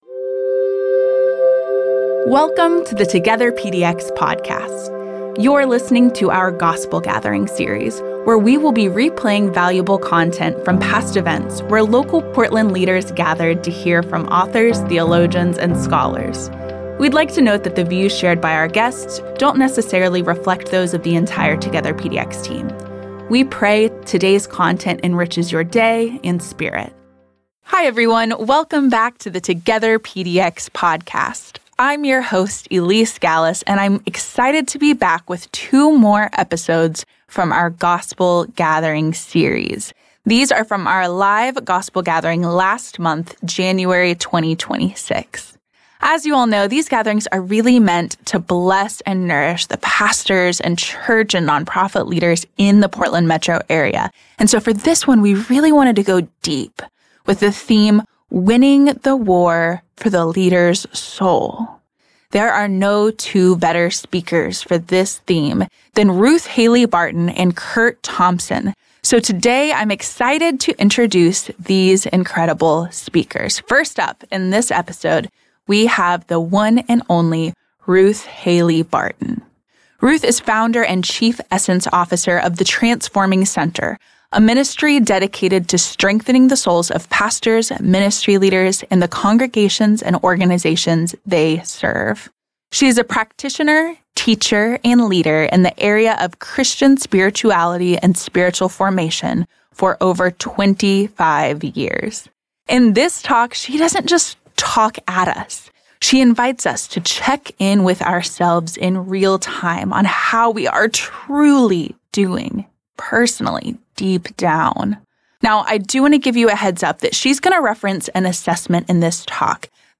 In this in-person gathering in January 2026